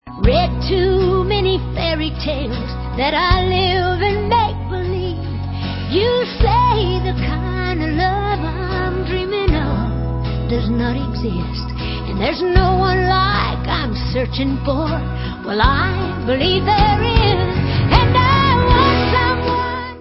sledovat novinky v oddělení Rockabilly/Psychobilly